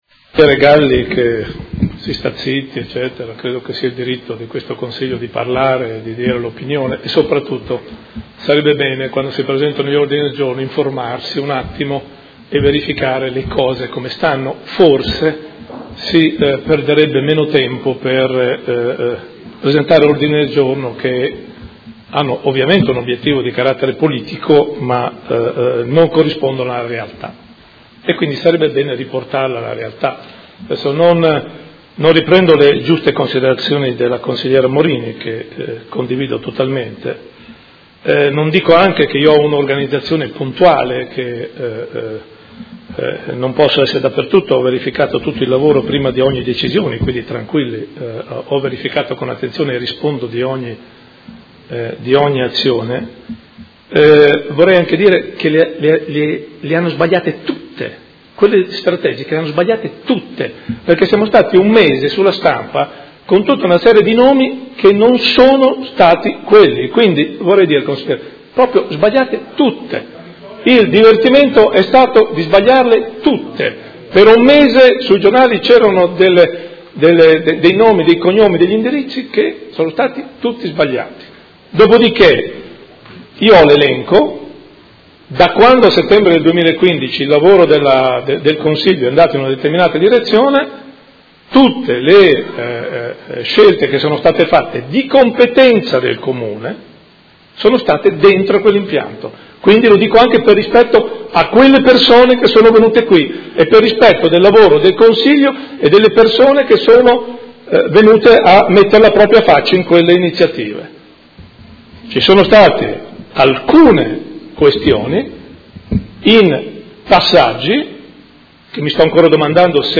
Seduta del 6 ottobre.
Dibattito